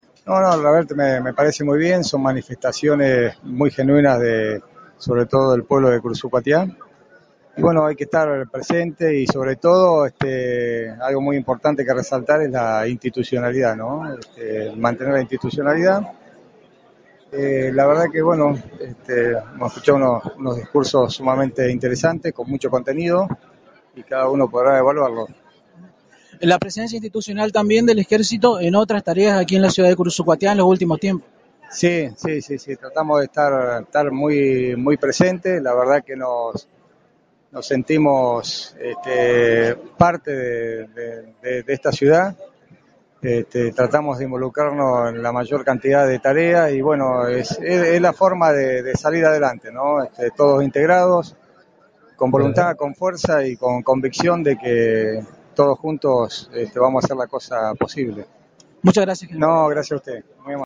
Minutos después de haber presenciado el acto oficial de inauguración de la 49º Feria del Ternero Correntino, IusNoticias tuvo un breve diálogo con el General del Ejército Argentino Guillermo Tabernero quien destacó los discursos vertidos ante las gradas de la Sociedad Rural de Curuzú Cuatiá señalando que "hay que estar presentes" como institución y justamente remarcó la importancia de "mantener la institucionalidad".
Escuchá al General